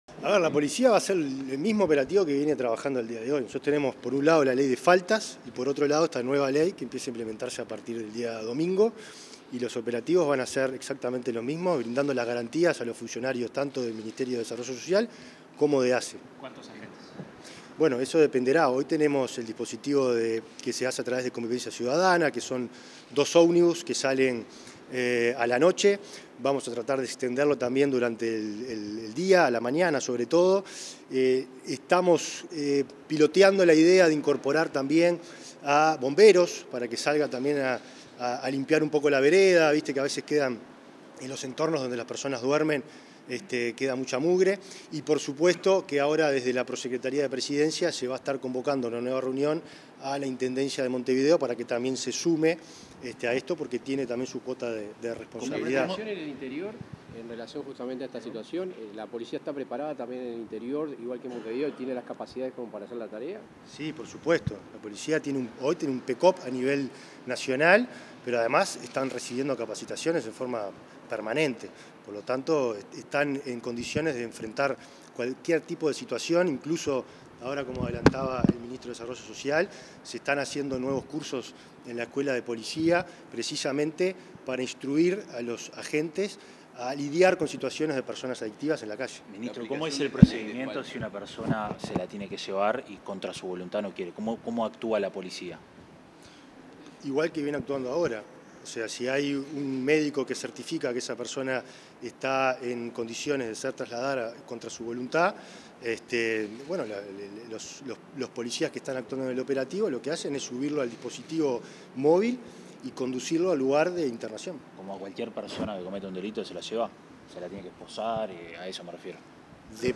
Declaraciones del ministro del Interior, Nicolás Martinelli
Declaraciones del ministro del Interior, Nicolás Martinelli 22/08/2024 Compartir Facebook X Copiar enlace WhatsApp LinkedIn Luego de una reunión con el presidente de la República a fin de concretar detalles para implementar la ley de internación compulsiva, este 22 de agosto, el ministro del Interior, Nicolás Martinelli, realizó declaraciones a la prensa.